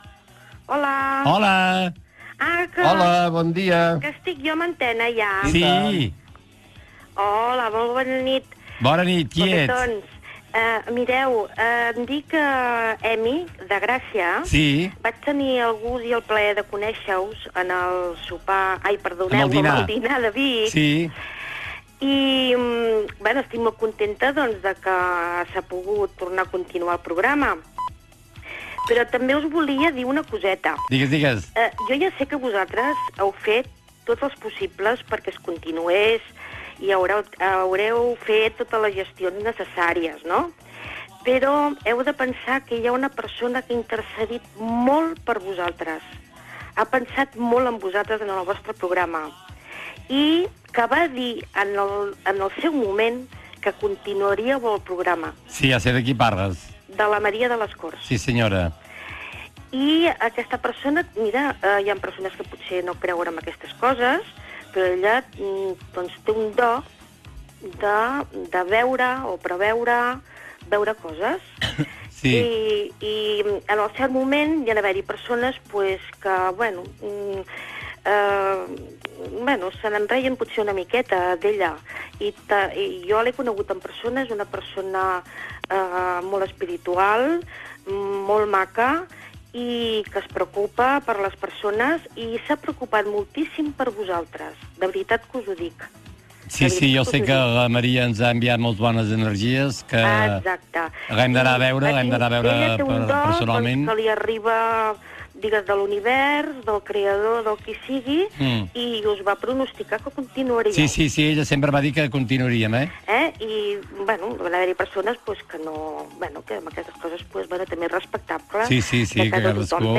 Converses telefòniques amb els oients del programa